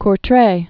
(kr-trā, kr-)